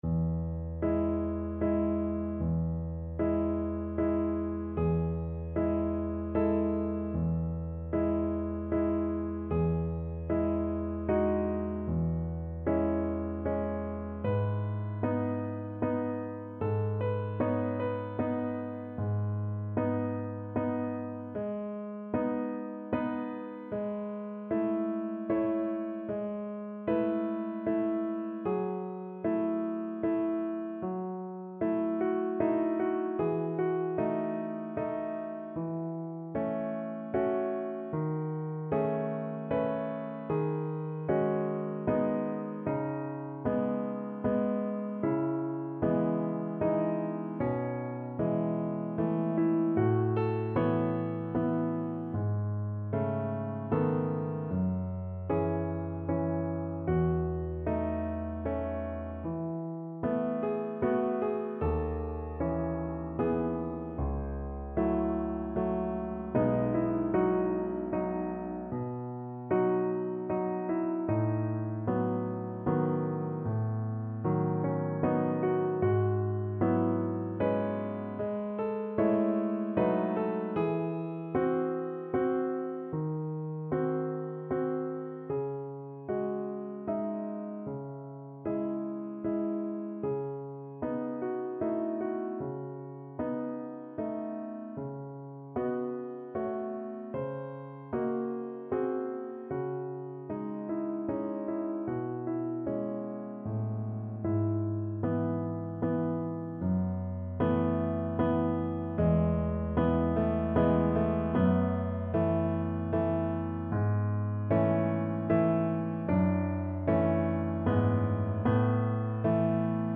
Piano version
No parts available for this pieces as it is for solo piano.
Adagio assai =76
3/4 (View more 3/4 Music)
Piano  (View more Intermediate Piano Music)
Classical (View more Classical Piano Music)